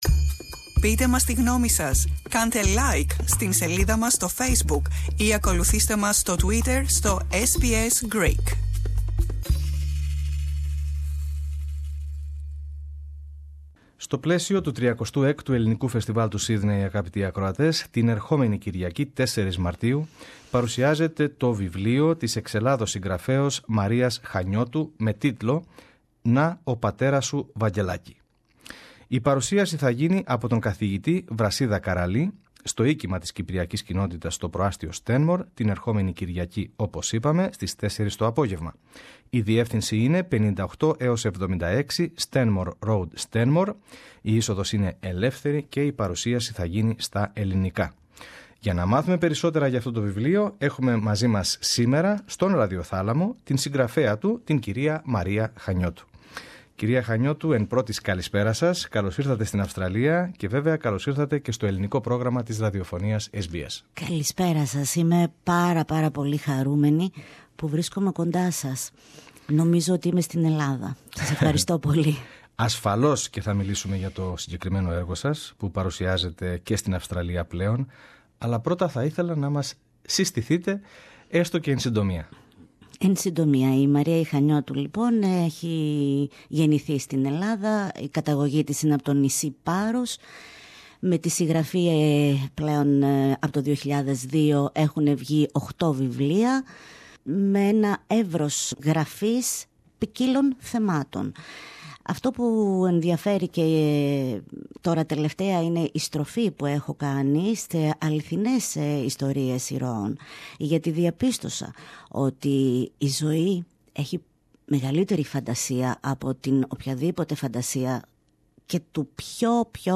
Το ιστορικό αυτό μυθιστόρημα πραγματεύεται τα βιώματα ενός ορφανού από την Καταστροφή του 1922 ως το Δεύτερο Παγκόσμιου Πόλεμο και τον Εμφύλιο. Περισσότερα ακούμε στη συνέντευξη